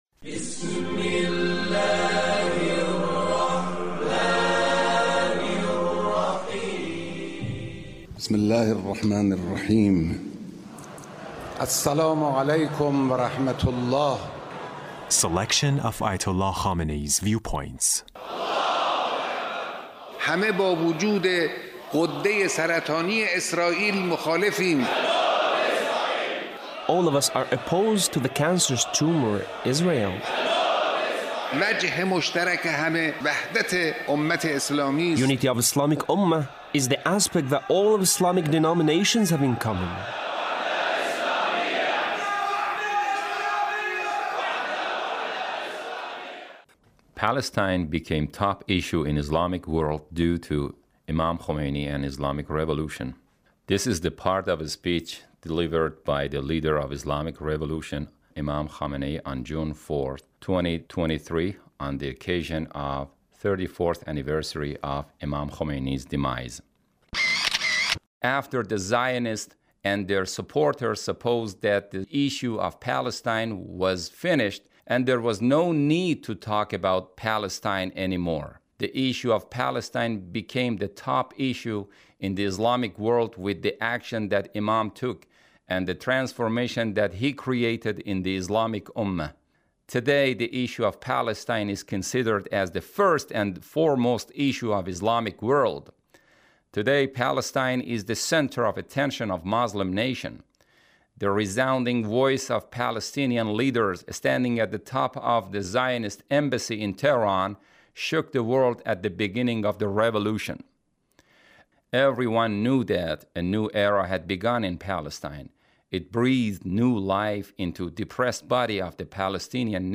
Leader's Speech (1734)
Leader's Speech on the occasion of the 34th anniversary of Imam Khomeini’s demise. 2023